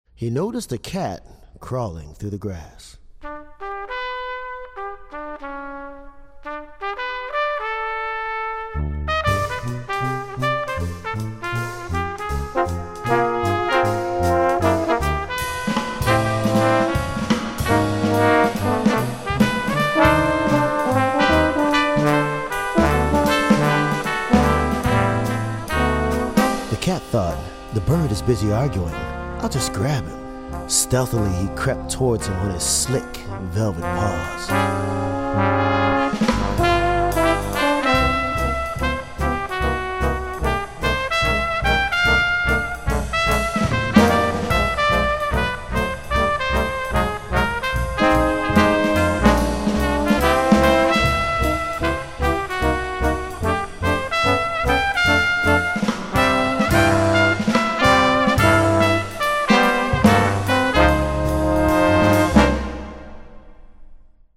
arranged Prokofievs's classic childrens work for 5 brass, one percussion, and a narrator